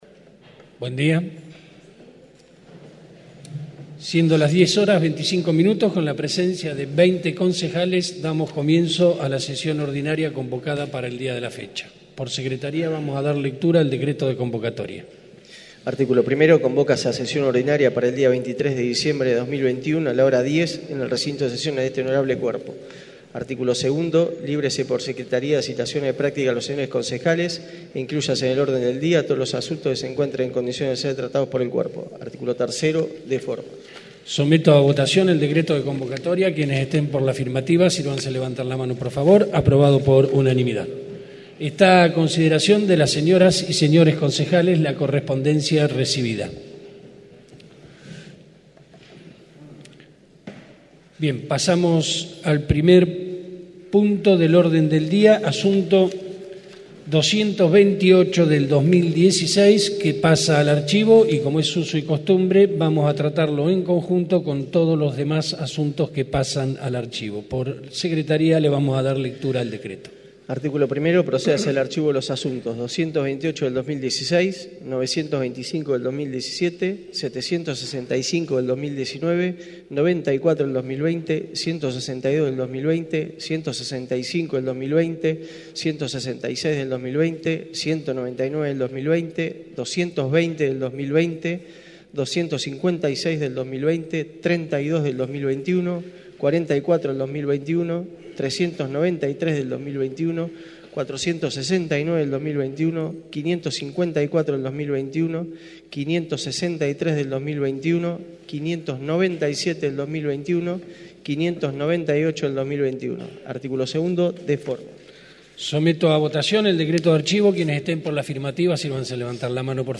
Audios de sesiones
Asamblea de Concejales y Mayores Contribuyentes